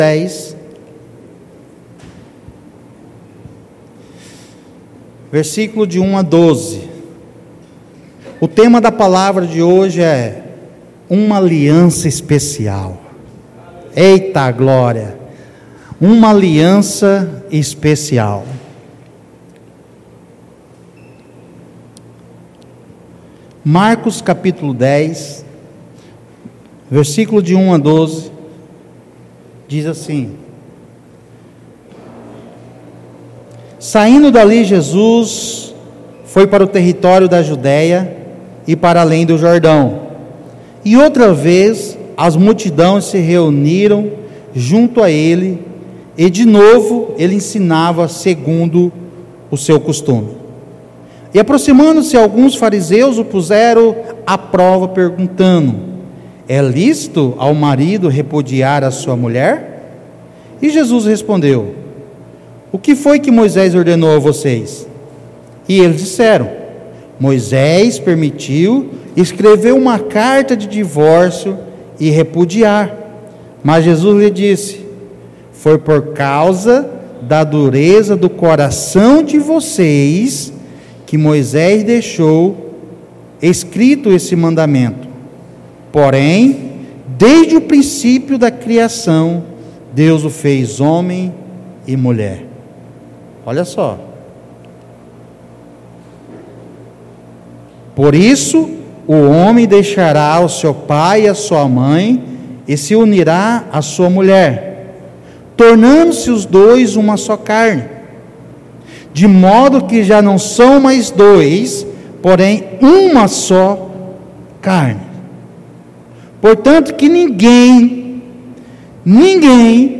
Culto de Celebração